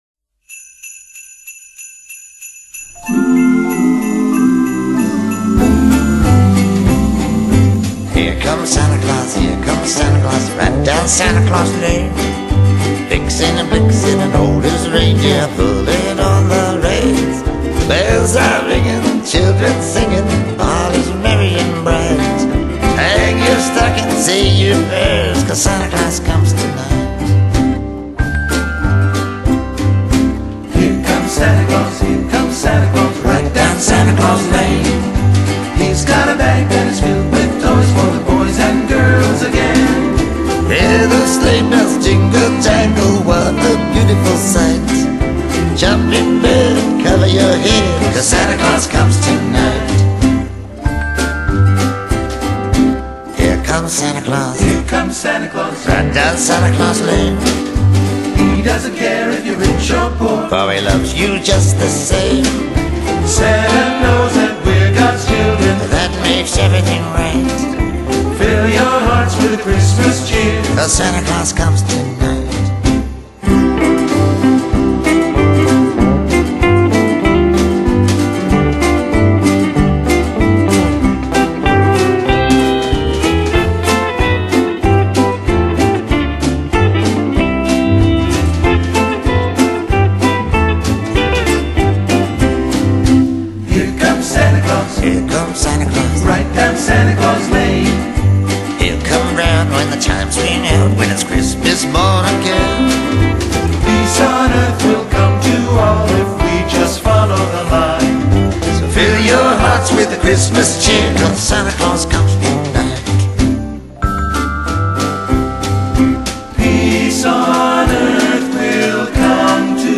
【2009'聖誕專輯】